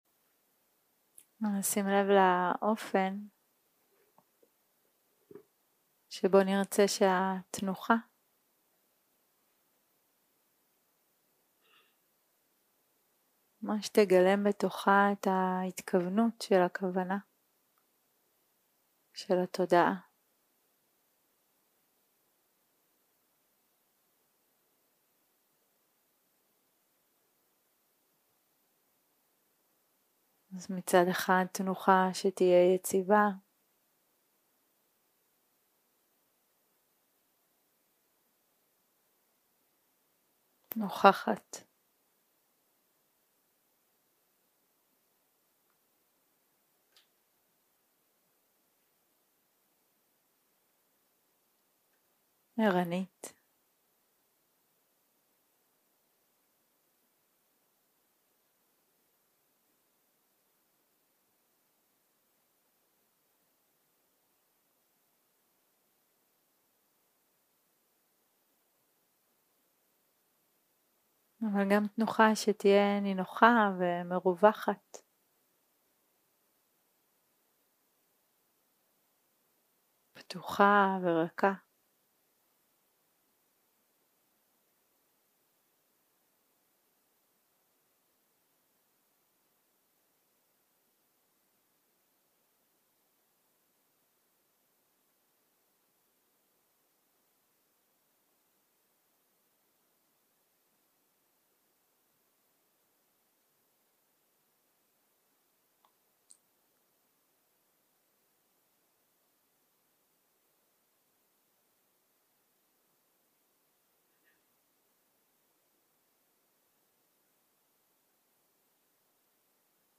יום 1 - הקלטה 1 - ערב - מדיטציה מונחית
Dharma type: Guided meditation